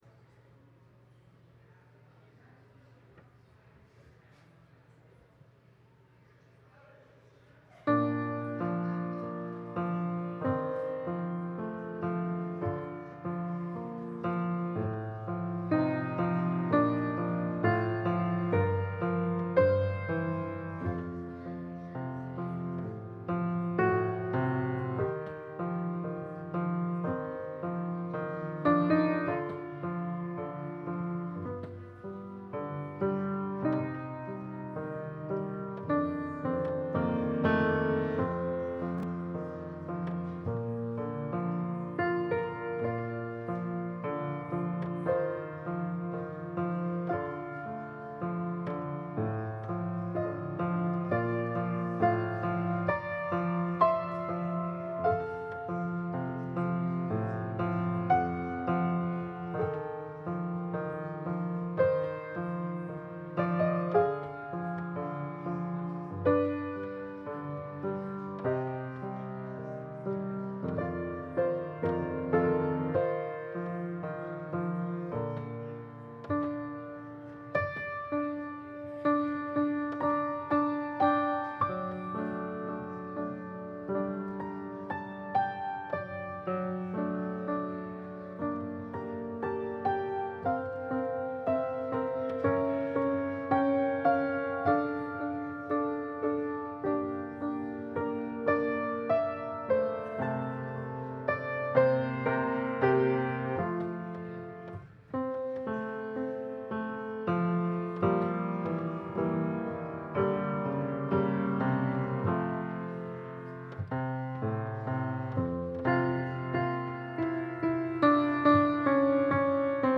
Passage: Psalm 3 Service Type: Sunday Service Scriptures and sermon from St. John’s Presbyterian Church on Sunday